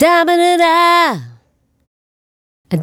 Ba Dabba Doo Da 085-B.wav